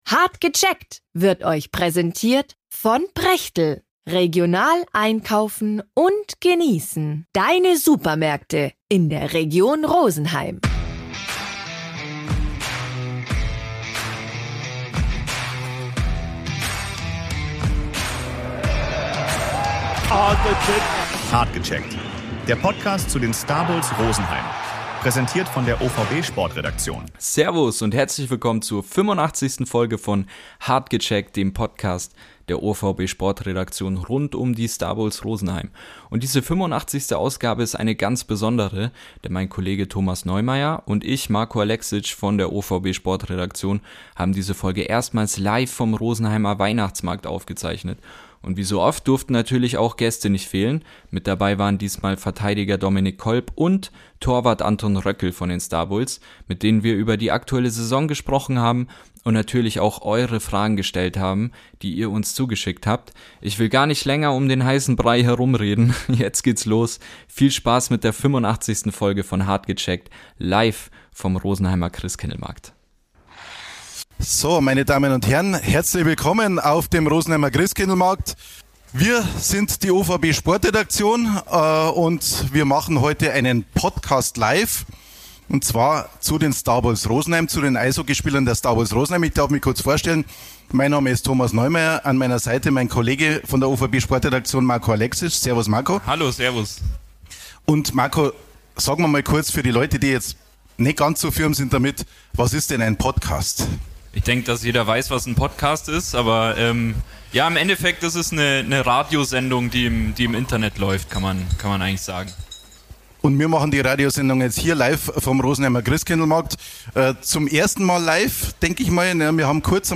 85. Folge: Live vom Christkindlmarkt Rosenheim ~ Hart gecheckt - Der OVB-Podcast zu den Starbulls Rosenheim Podcast